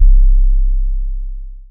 DIST4M808.wav.wav